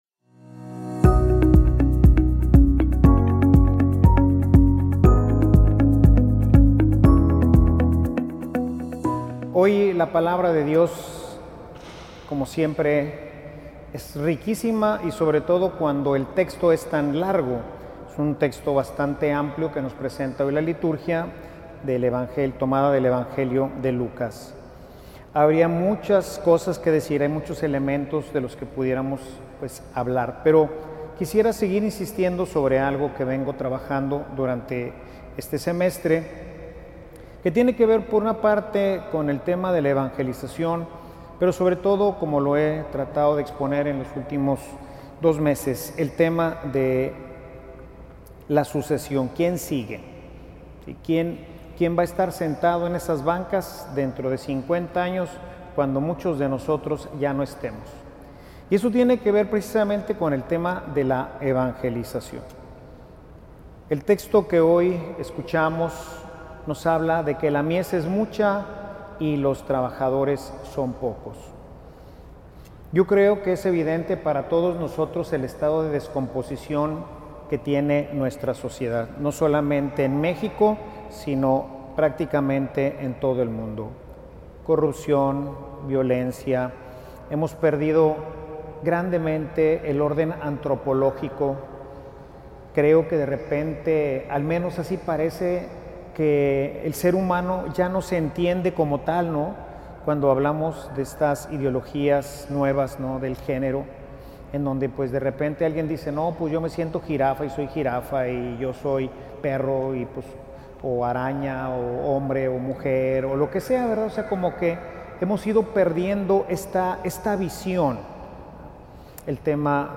Homilia_La_evangelizacion_es_cosa_de_todos.mp3